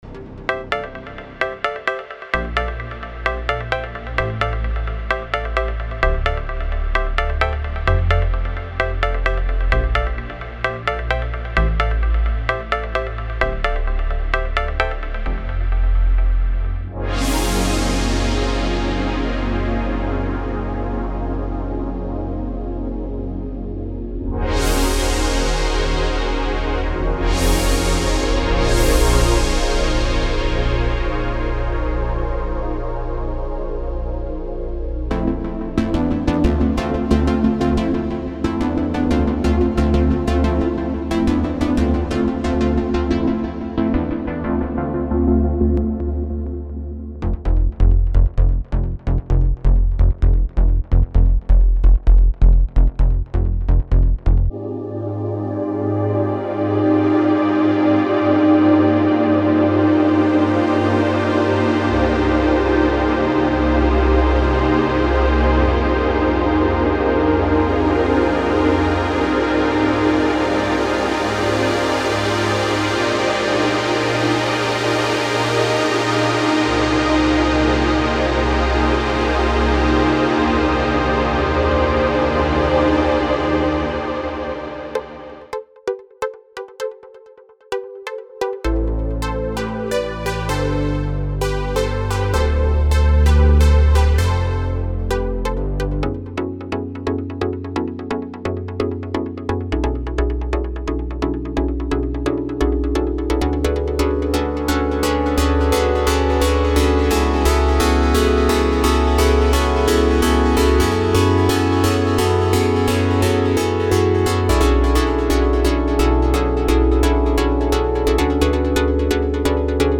Type: Spire Midi Templates Samples
Ambient Big Room Breaks Chill Out / Lounge Cinematic / FX Deep House Drum & Bass Future Rave House Industrial Melbourne Bounce Melodic Techno Multi-genre Progressive House Psy-Trance Synthwave / Retrowave Techno Trance Uplifting Trance